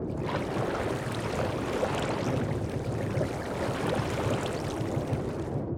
inside_underwater1.ogg